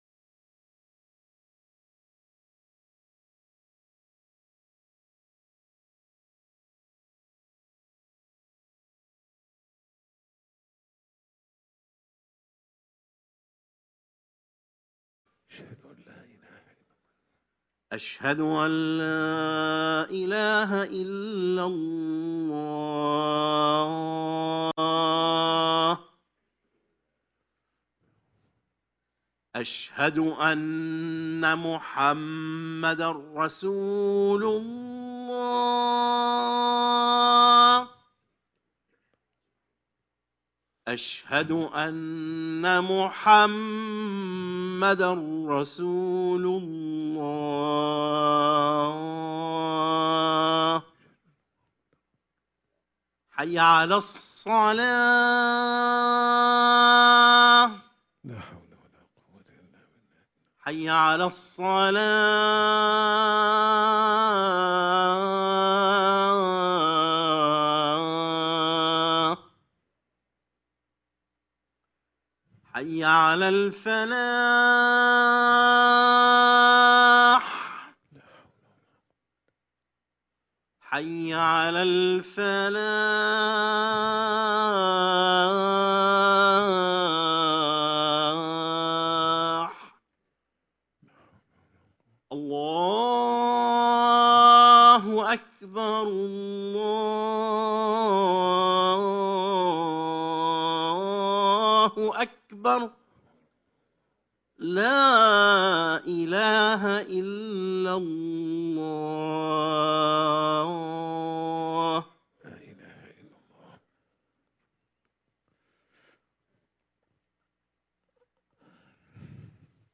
خطبة الجمعة - وجاهدهم به جهاداً كبيراً